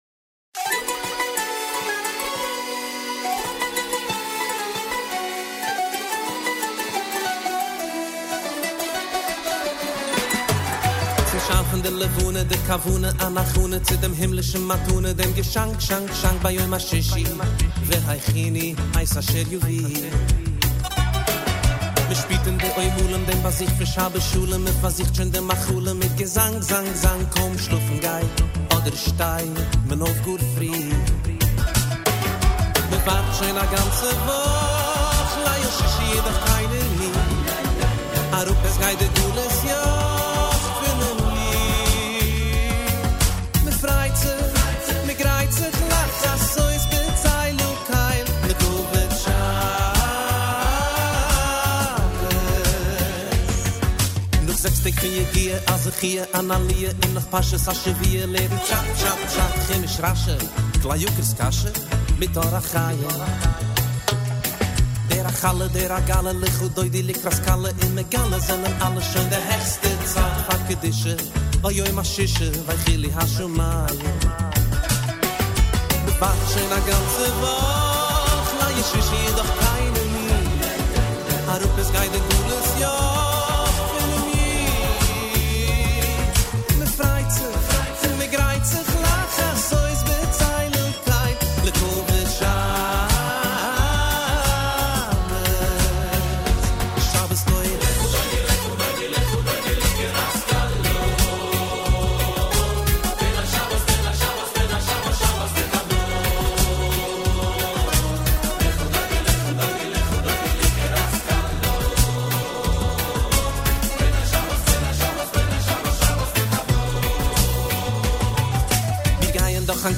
מחרוזת אווירה שבתית.mp3 הייתי זקוק להשראה בשביל ההכנות…ובחרתי שירים לדעתי נעימים ערכתי וקצת מאסטרינג ואיזה כייף